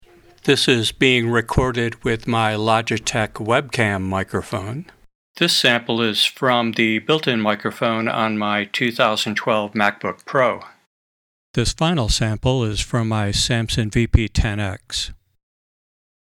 Microphone Considerations
While the built-in microphone on the MacBook or iPhone is acceptable, you should be aware of the difference in quality obtained by even low-end professional mics. Here's a quick comparison.